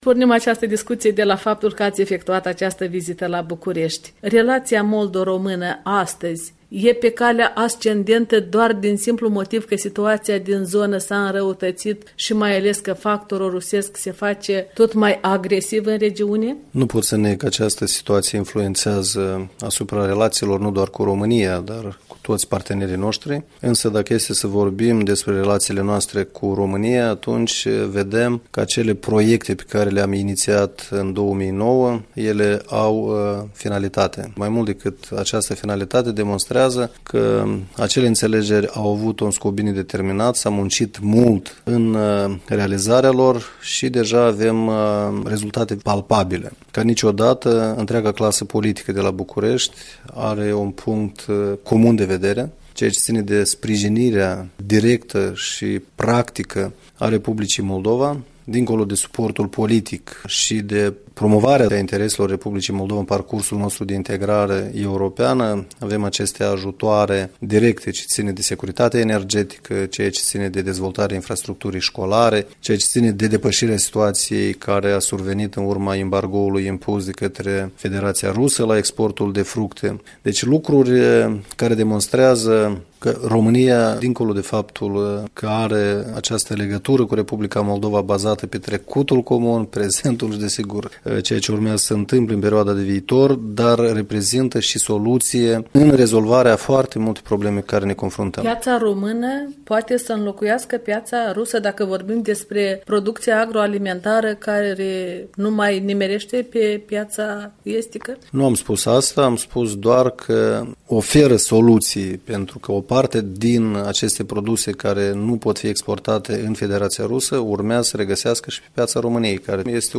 Interviul cu Vlad Filat, președintele PLDM, la întoarcerea sa din România